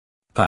/ p 48
Phoneme_(Commonscript)_(Accent_0)_(48)_(Male).mp3